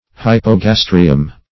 Search Result for " hypogastrium" : The Collaborative International Dictionary of English v.0.48: Hypogastrium \Hyp`o*gas"tri*um\, n. [NL., fr. Gr.